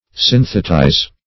Search Result for " synthetize" : The Collaborative International Dictionary of English v.0.48: Synthetize \Syn"the*tize\, v. t. [Cf. Gr. ?.] To combine; to unite in regular structure.